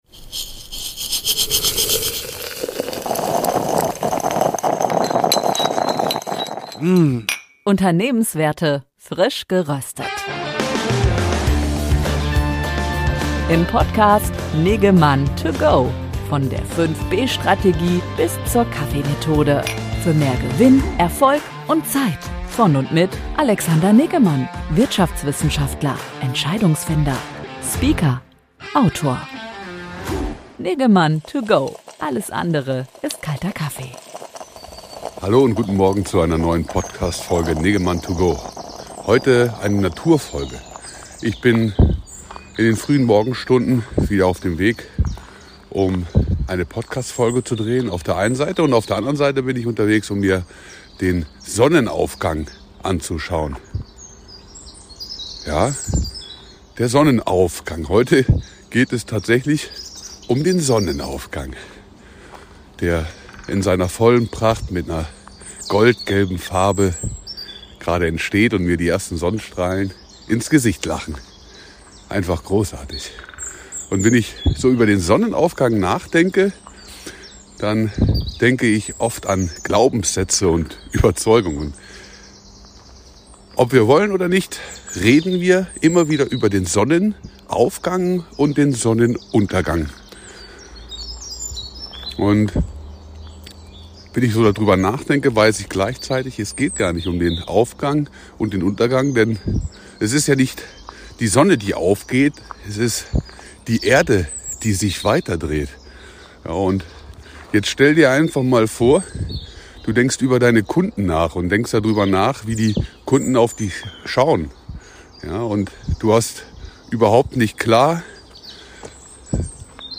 Morgenroutine: dem Spaziergang Richtung Sonnenaufgang!